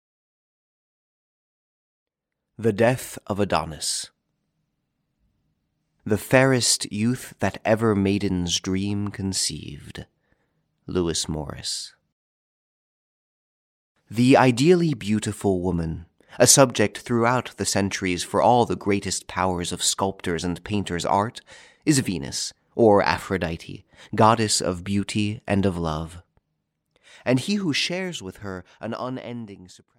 The Death Of Adonis, Greek Mythology (EN) audiokniha
Ukázka z knihy